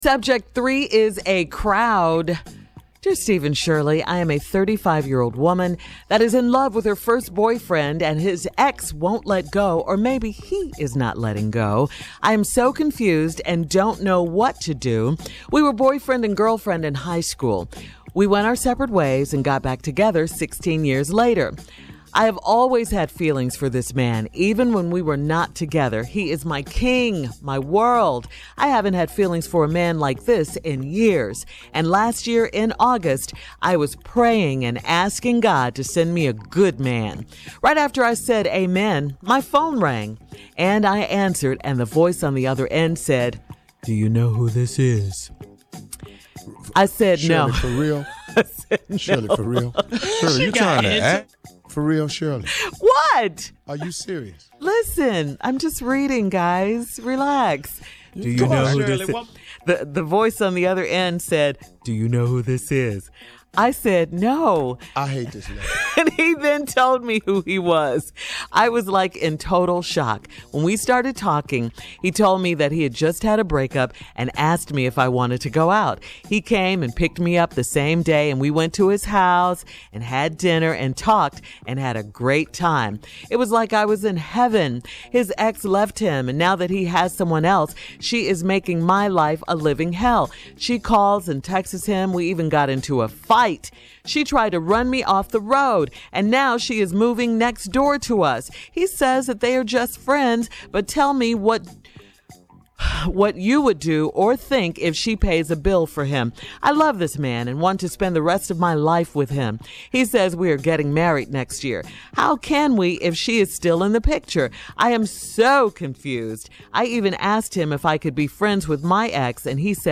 LISTEN TO STEVE AND SHIRLEY COMMENT ON THIS LETTER BELOW: